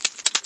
PixelPerfectionCE/assets/minecraft/sounds/mob/spider/step3.ogg at mc116